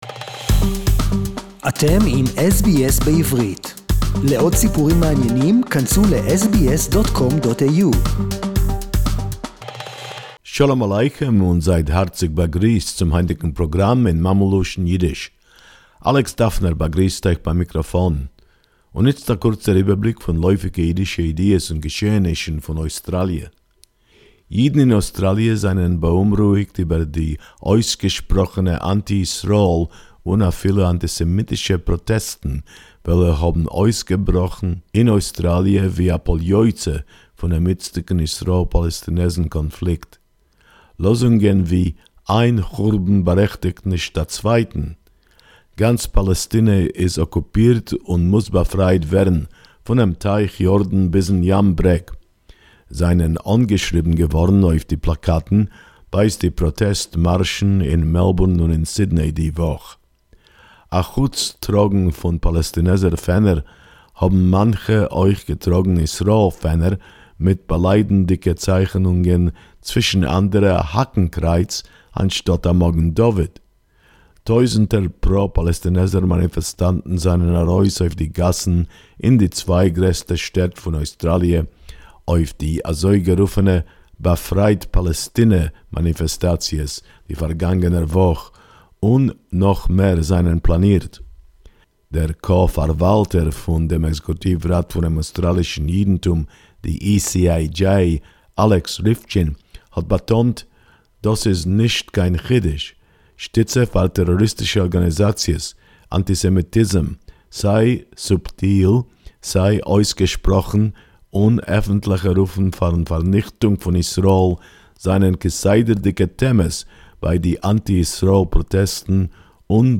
SBS Yiddish report